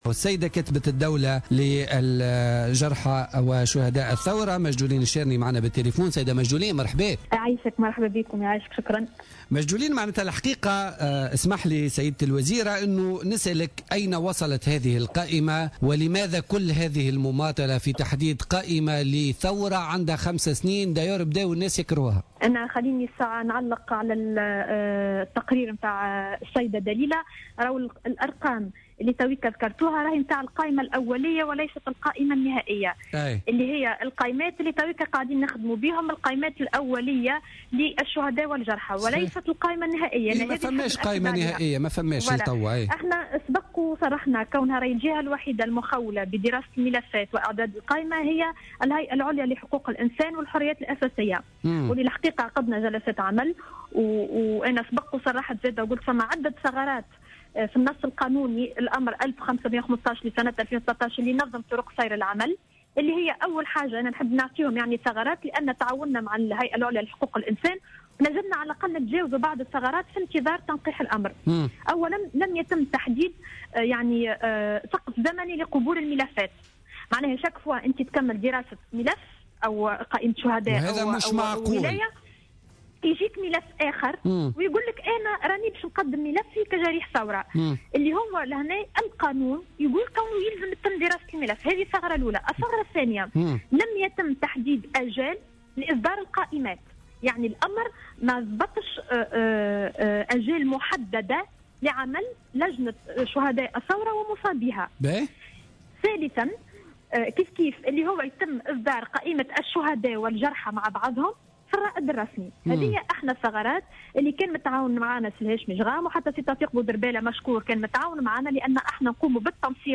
كشفت كاتبة الدولة لدى وزير الشؤون الإجتماعية المكلفة بملف شهداء وجرحى الثورة، ماجدولين الشارني وضيفة برنامج بوليتكا لليوم الاثنين 07 ديسمبر 2015 أن عدد ملفات شهداء وجرحى الثورة قد بلغ إلى حد الآن 8000 ملف وفق التقرير الأولي للهيئة العليا المستقلة لحقوق الإنسان والحريات الأساسية.